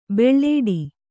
♪ beḷḷēḍi